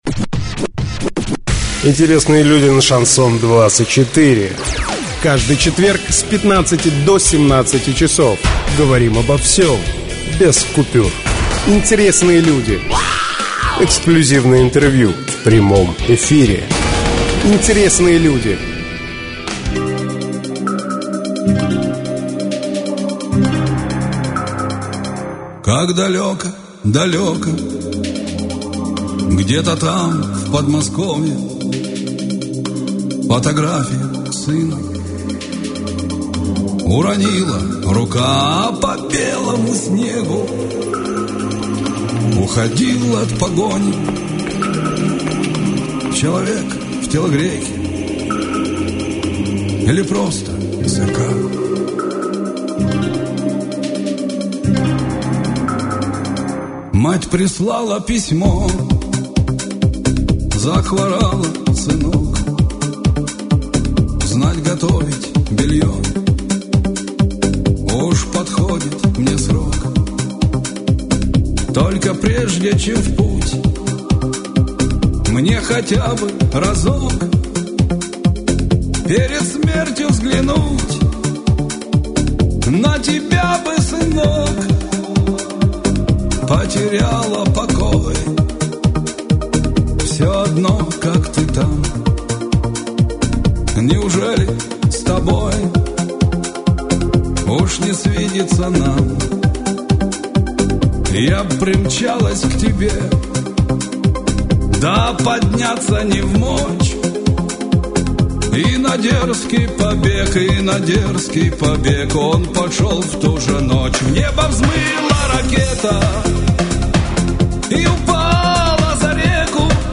Иван Кучин Интервью 2011 года